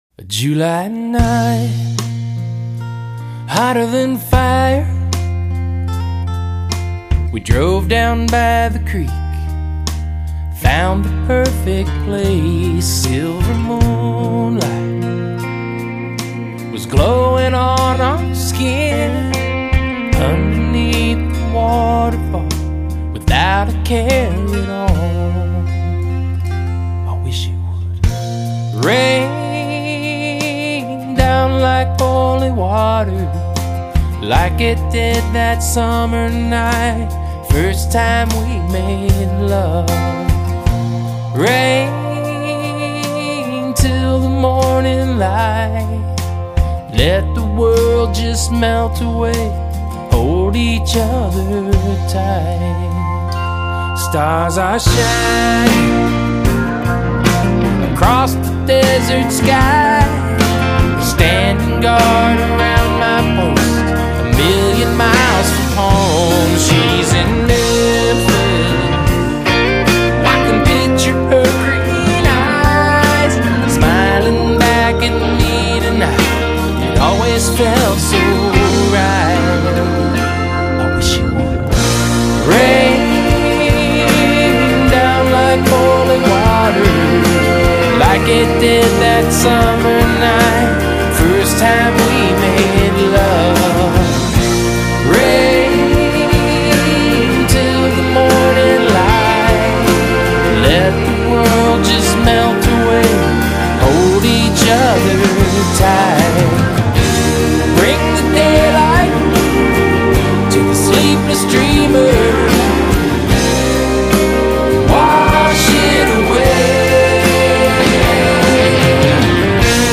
Lead vocal, acoustic guitar, harmonica
Backing vocals
Electric and acoustic guitars
Organ
Bass
Drums
Percussion
Pedal steel